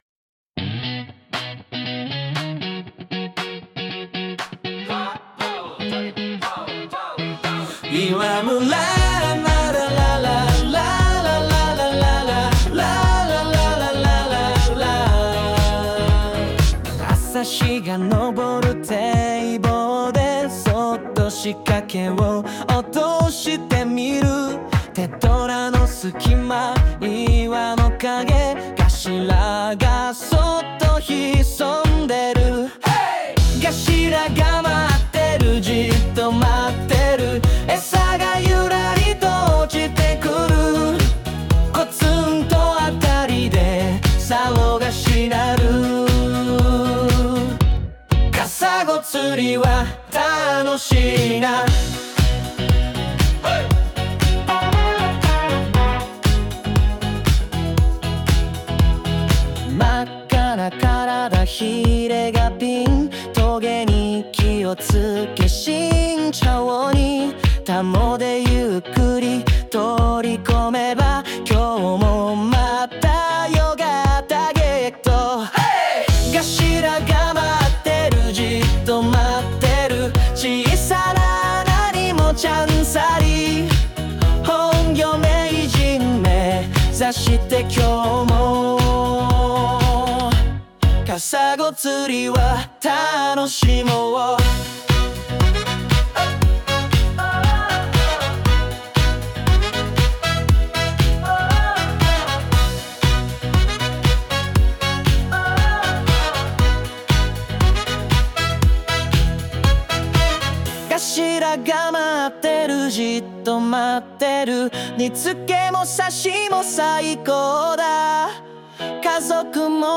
作詞、作曲：釣太郎 with Suno AI , ChatGPT